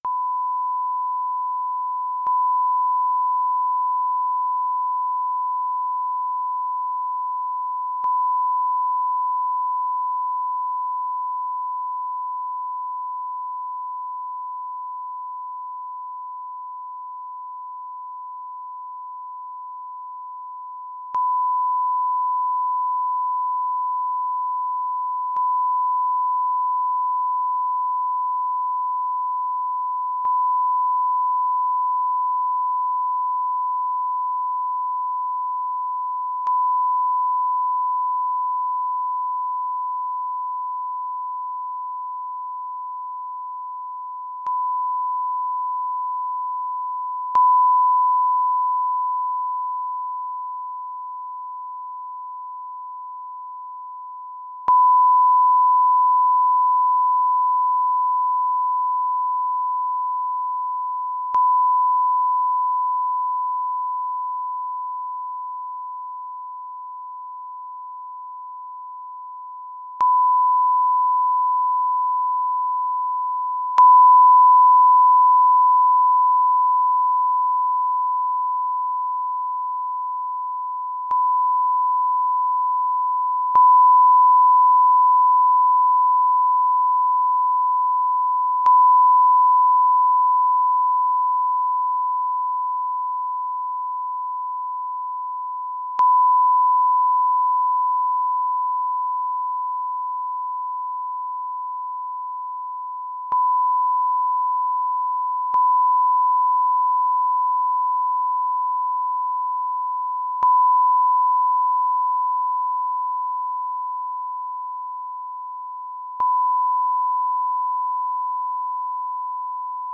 Также преобразованы в звук зарегистрированные "Венерами" электромагнитные импульсы грозовых разрядов (промодулированы соответствующим спектром).
"Венера-11", 10 000 Гц, t = 6:05:30-6:12, промодулировано 1000 Гц (f/10):